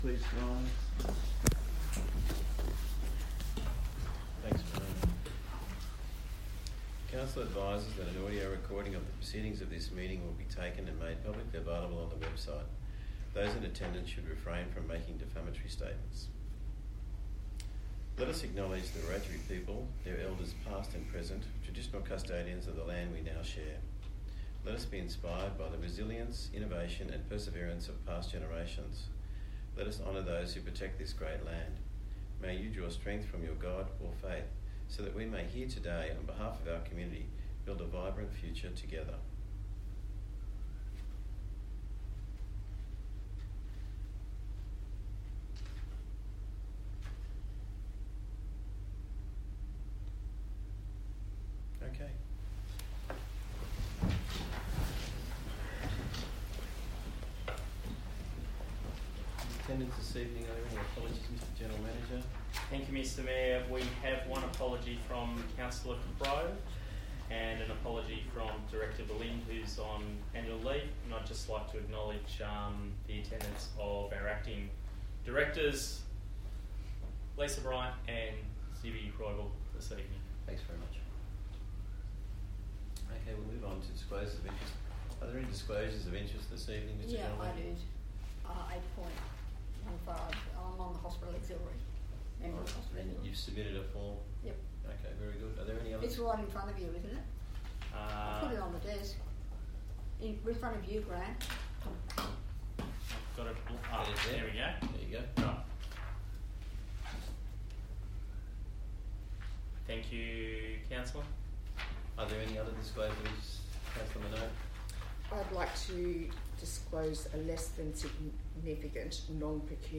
19 November 2024 Ordinary Council Meeting
Bland Shire Council Chambers, 6 Shire Street, West Wyalong, 2671 View Map